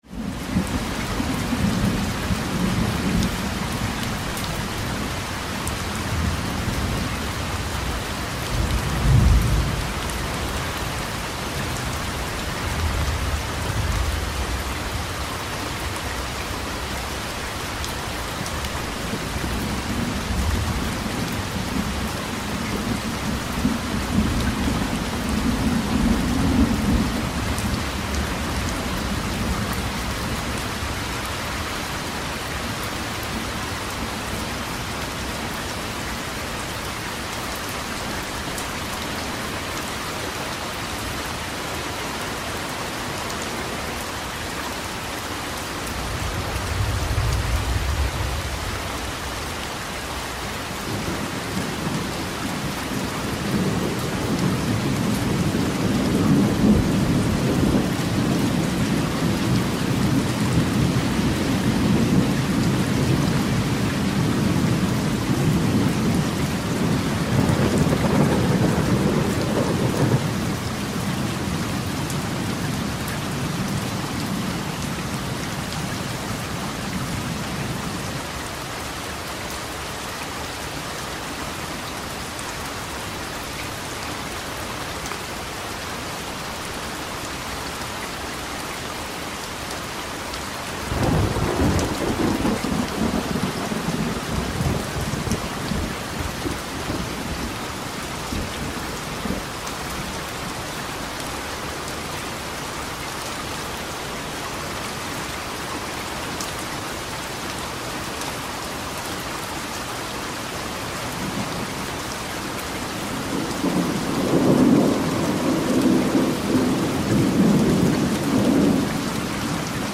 Звуки белого шума
На этой странице собраны разнообразные звуки белого шума, включая классическое шипение, помехи от телевизора и монотонные фоновые частоты.
Звучание белого шума в разных вариантах.